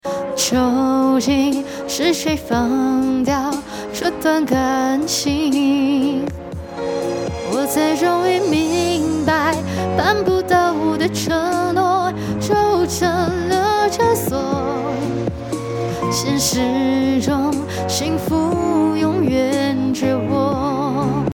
语音：中文